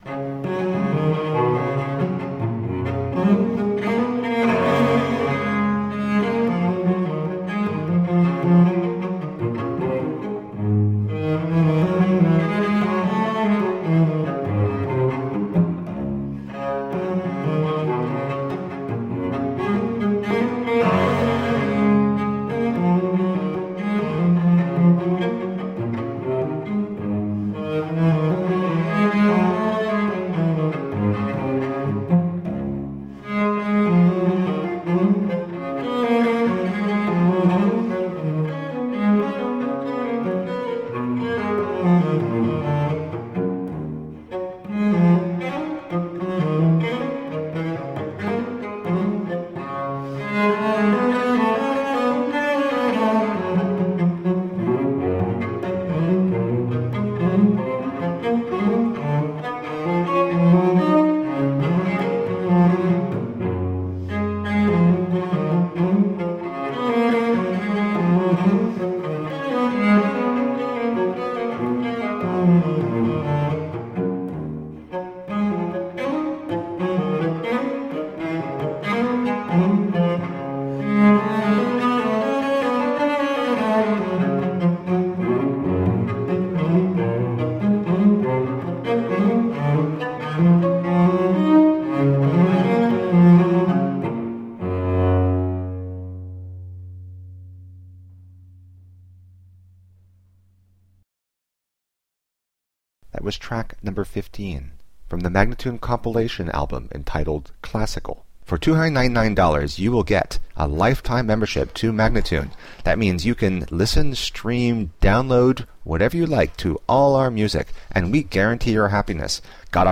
Cello
in G Major Gigue